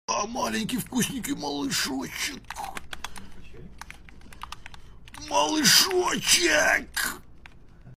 mellstroi malyshochek Meme Sound Effect